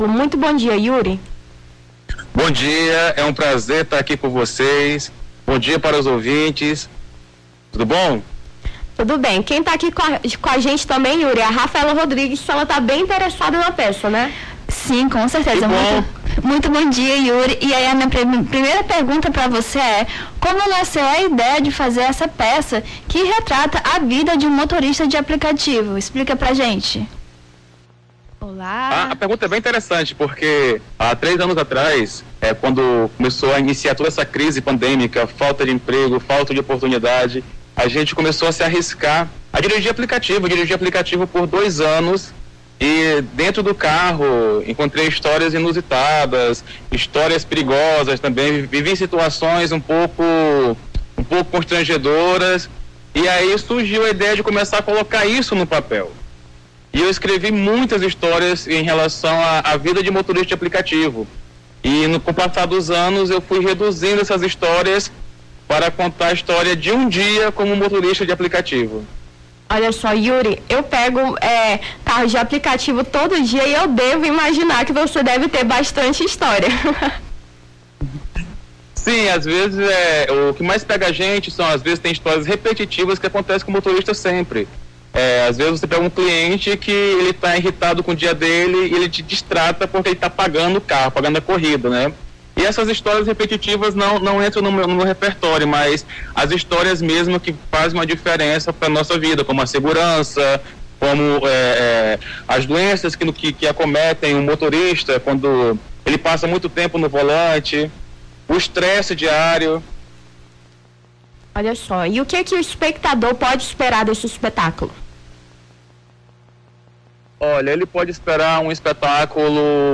Nome do Artista - CENSURA - ENTREVISTA (ESPETACULO COTIDIANO) 16-06-23.mp3